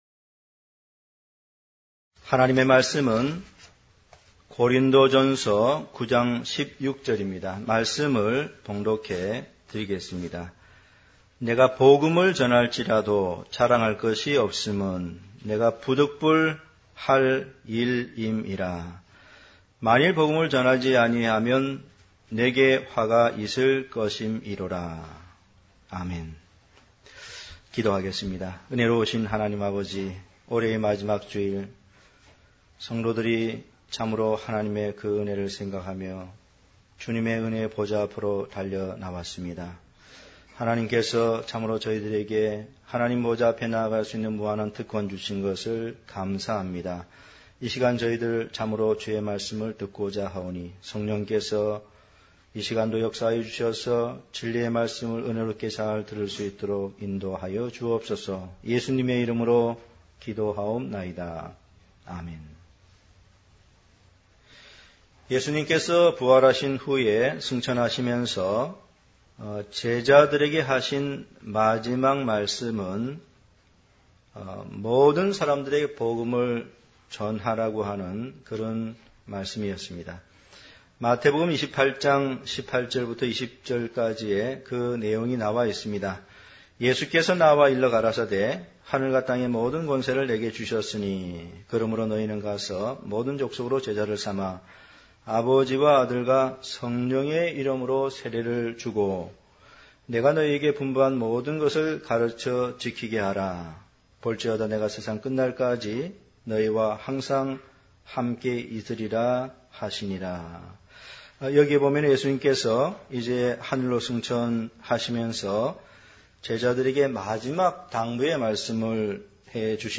단편설교